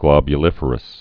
(glŏbyə-lĭfər-əs)